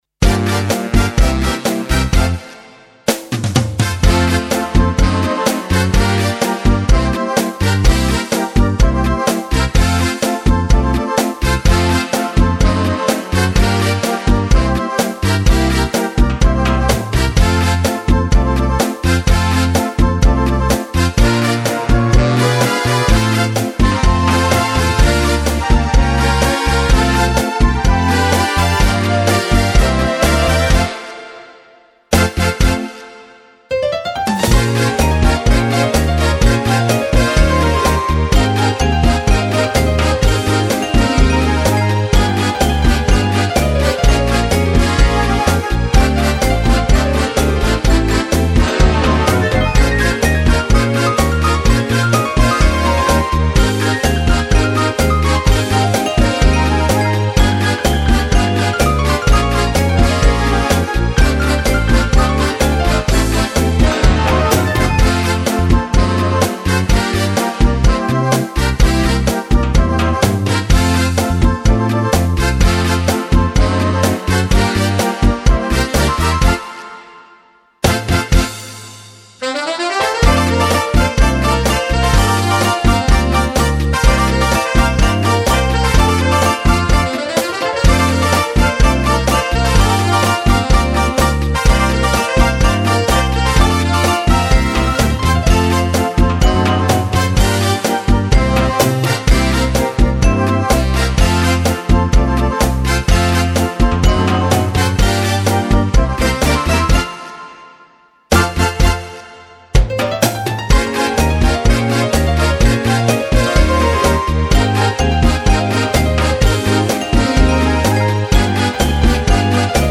Cha cha cha. Orchestra